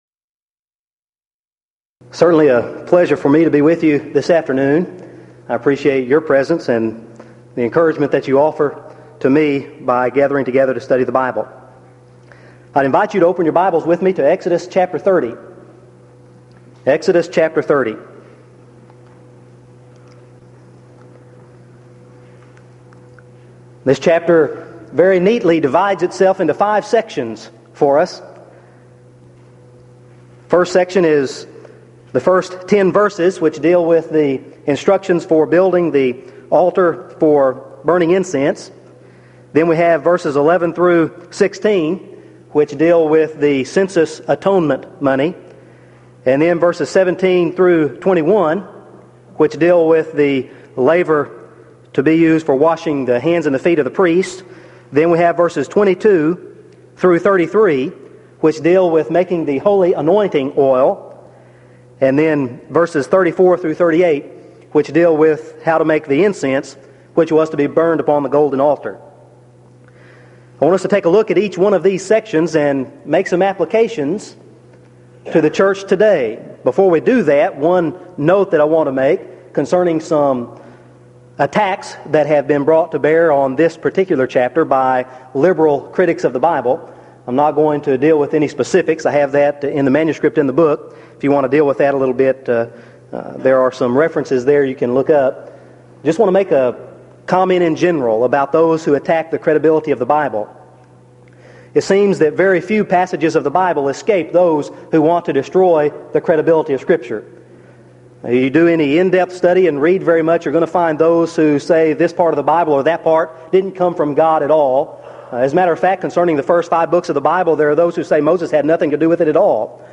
Event: 1997 East Tennessee School of Preaching Lectures Theme/Title: Studies In The Book of Exodus
lecture